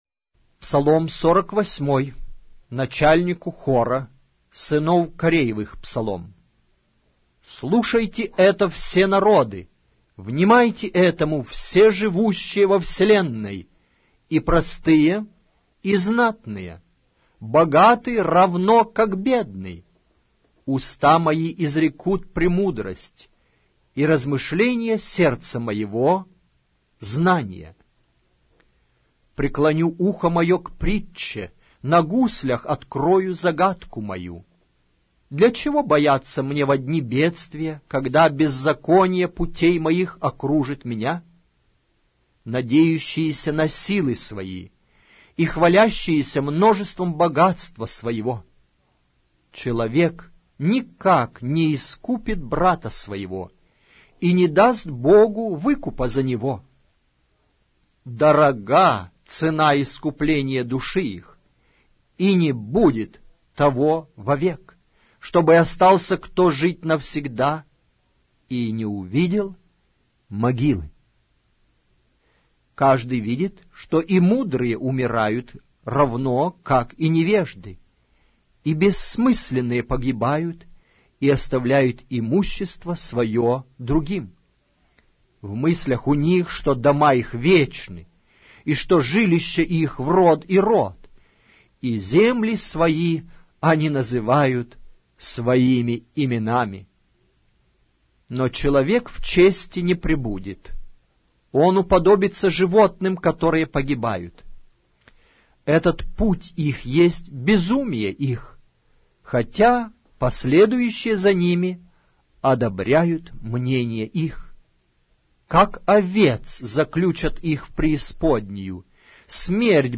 Аудиокнига: Псалтирь